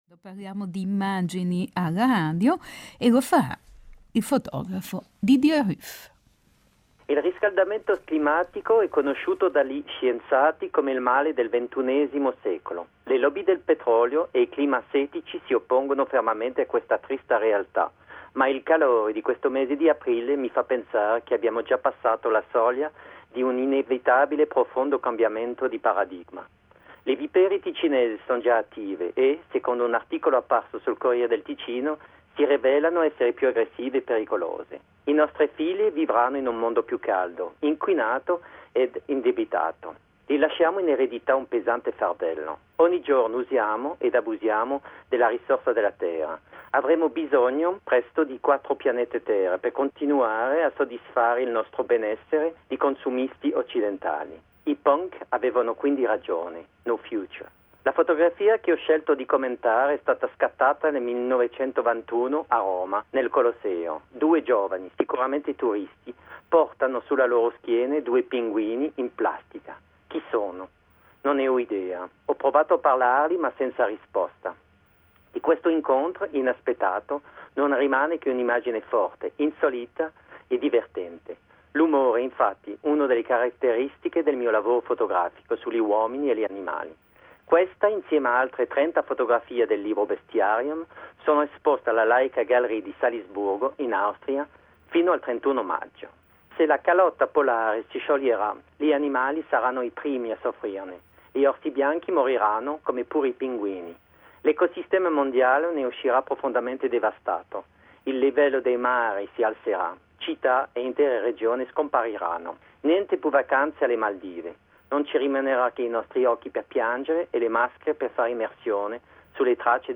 A radio comment about the image “Global warming”.